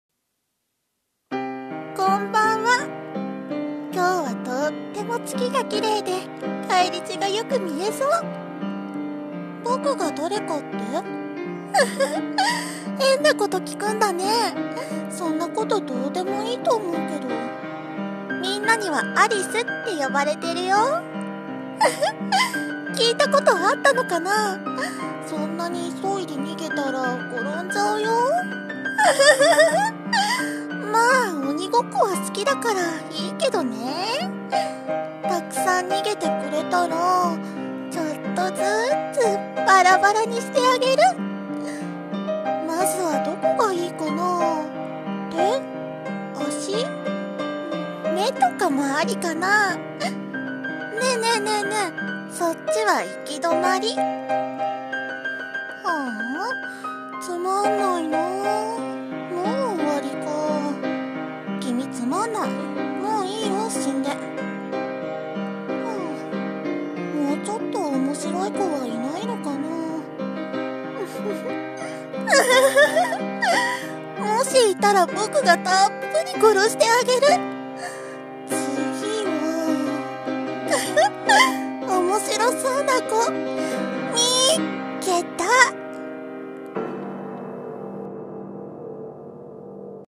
【声劇台本】死神アリス (一人声劇)